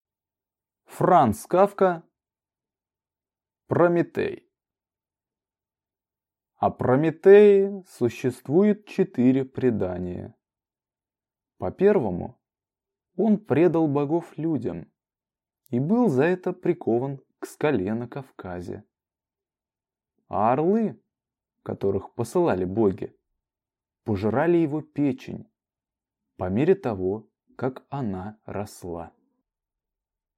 Аудиокнига Прометей | Библиотека аудиокниг